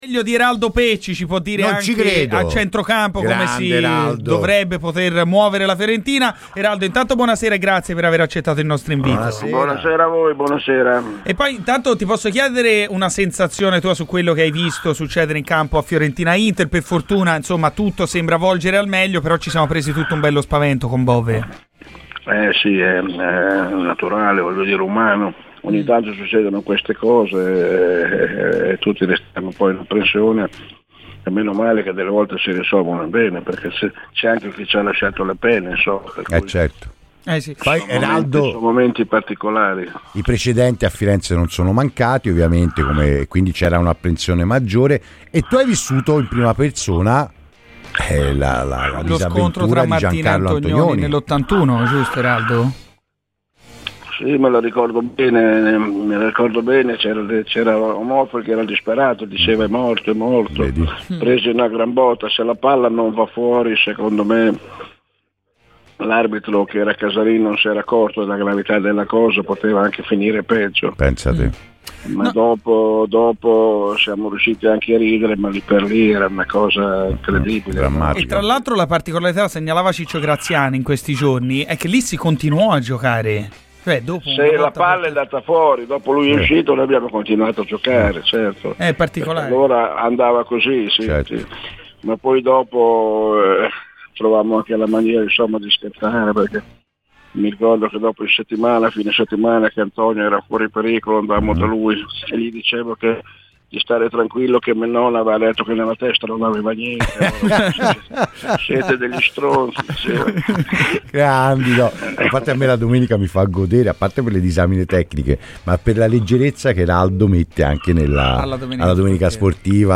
Per commentare il momento della Fiorentina l'ex centrocampista della Fiorentina Eraldo Pecci è intervenuto a Radio FirenzeViola durante Garrisca al Vento.